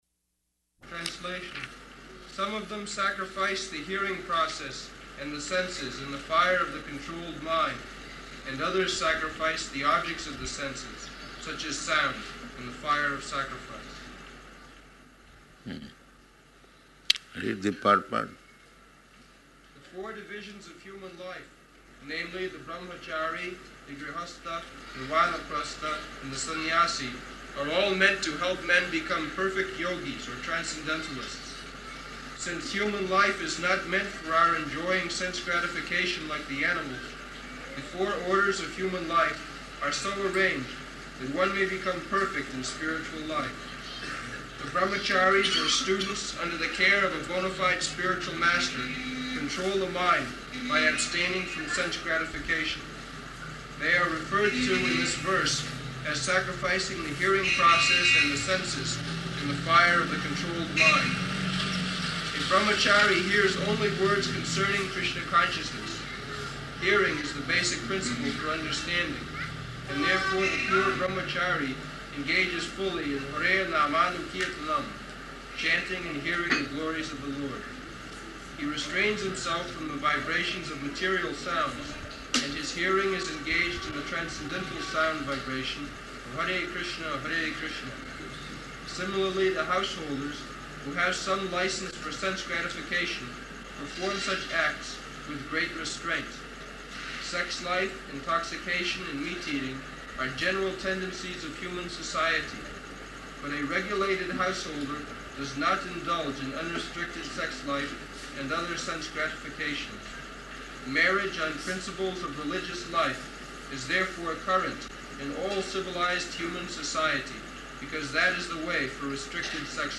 Type: Bhagavad-gita
Location: Bombay